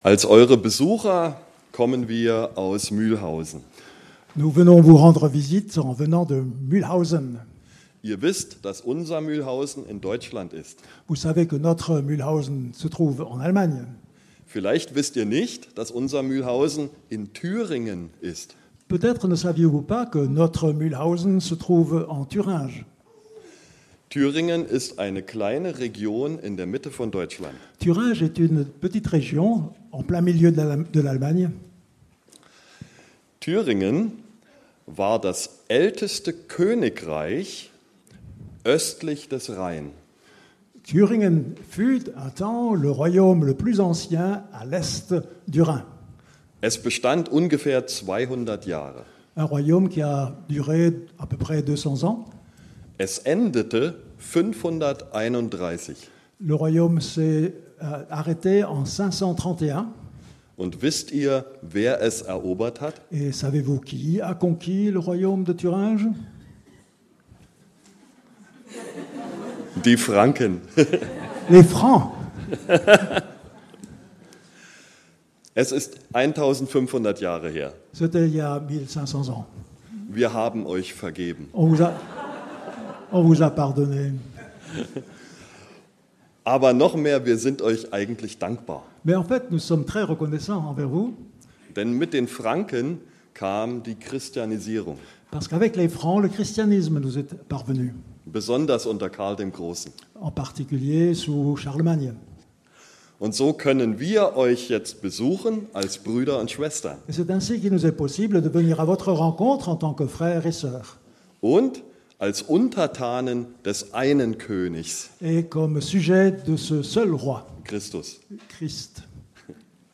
Culte du dimanche 30 novembre 2025 – Église de La Bonne Nouvelle